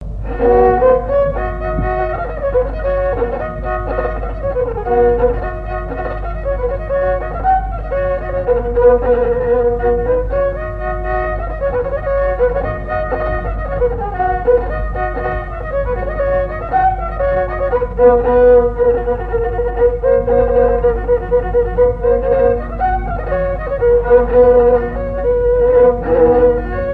danse : pas d'été
Assises du Folklore
Pièce musicale inédite